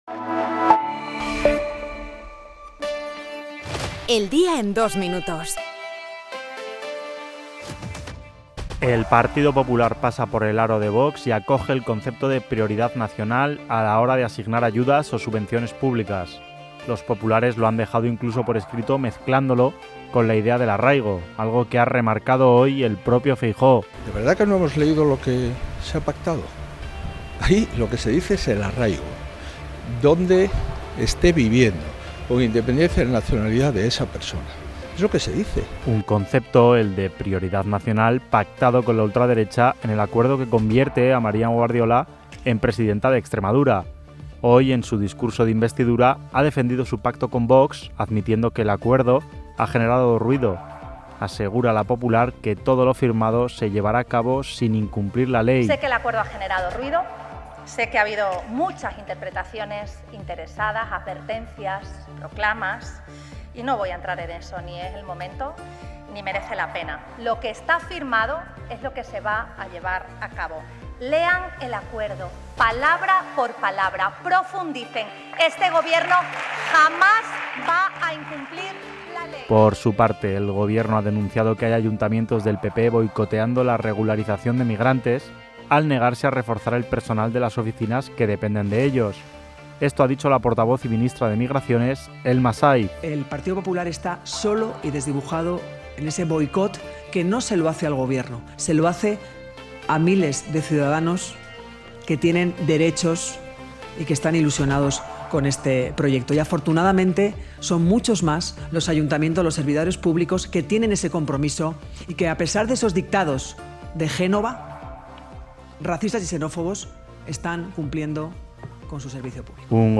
El resumen de las noticias de hoy